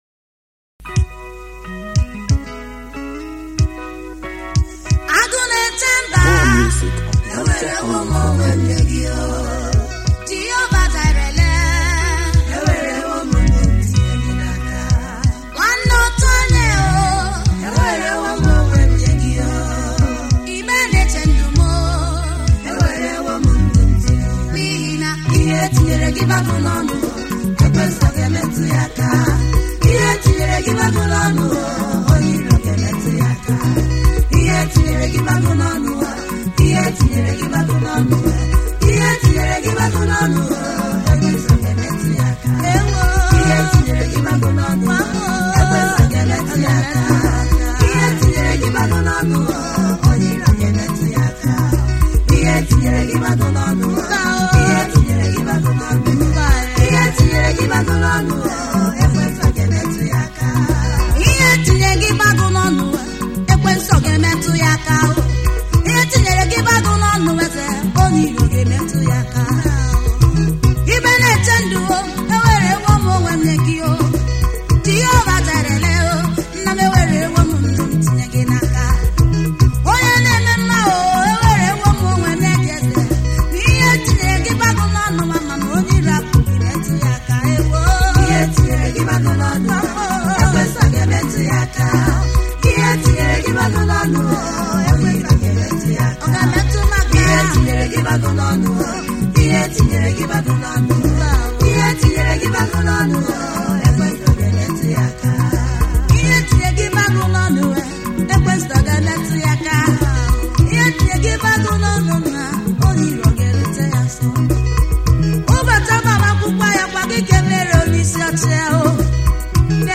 Igbo Gospel music